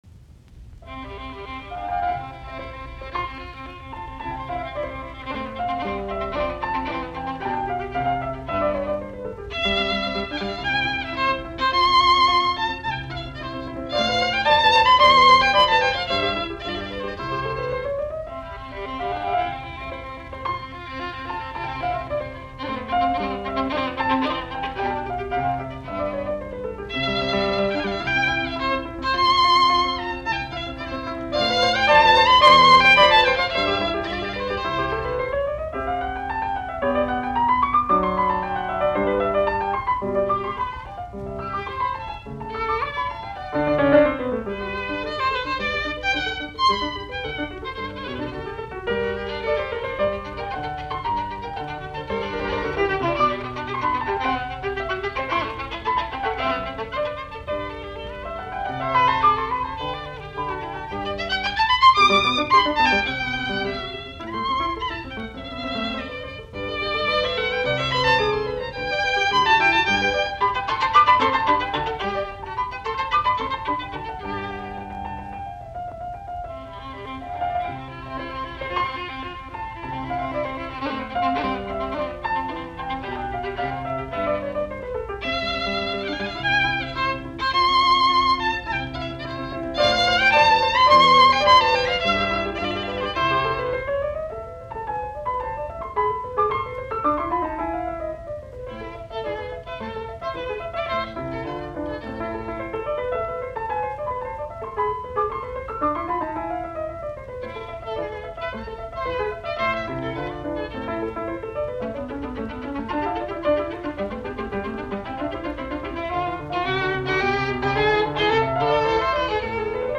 Soitinnus: Viulu, piano.